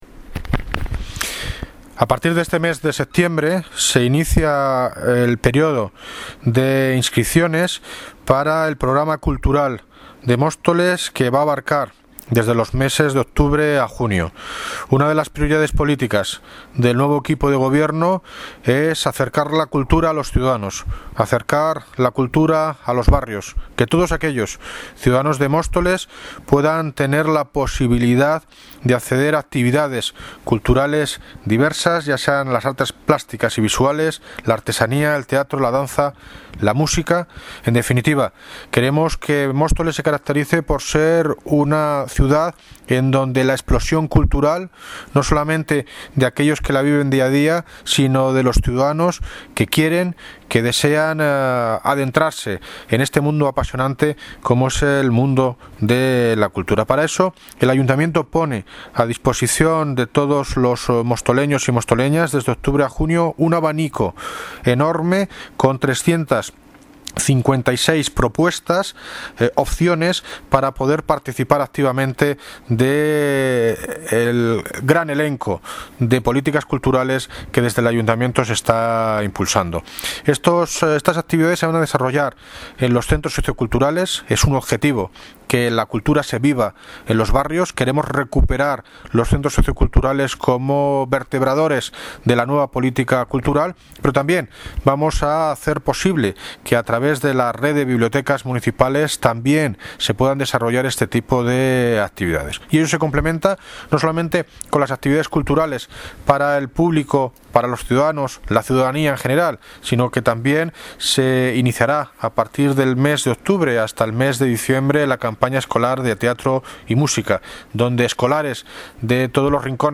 Audio - David Lucas (Alcalde de Móstoles) Sobre nueva temporada actividades culturales Mostoles